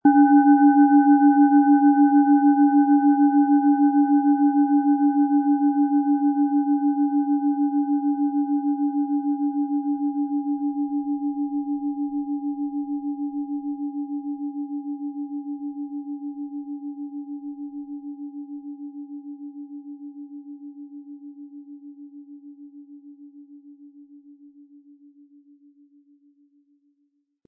Tibetische Bauch-Herz-Schulter- und Kopf-Klangschale, Ø 18,1 cm, 700-800 Gramm, mit Klöppel
Sie möchten den schönen Klang dieser Schale hören? Spielen Sie bitte den Originalklang im Sound-Player - Jetzt reinhören ab.
Aber dann würde der ungewöhnliche Ton und das einzigartige, bewegende Schwingen der traditionellen Herstellung fehlen.
Im Lieferumfang enthalten ist ein Schlegel, der die Schale wohlklingend und harmonisch zum Klingen und Schwingen bringt.
MaterialBronze